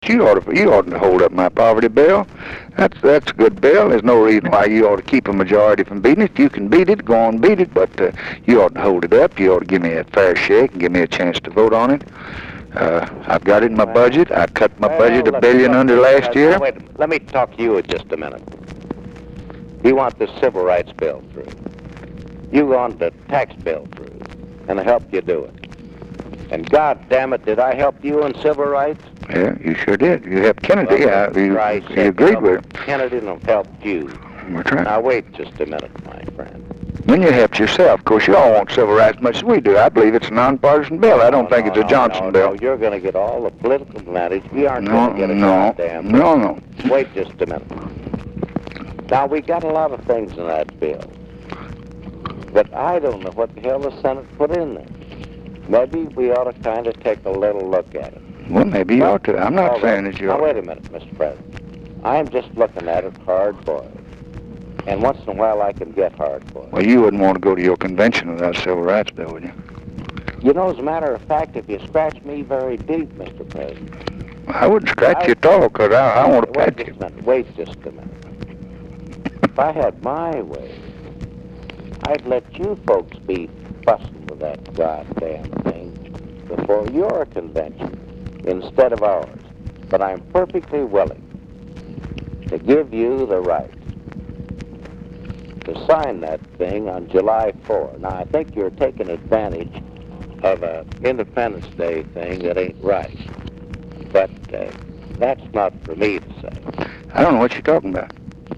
In this spring 1964 clip, Pres. Johnson and House minority leader Charlie Halleck debate the political effects of the 1964 civil rights bill–the signing of which, at the urging of AG Robert Kennedy, the President had scheduled for July 4.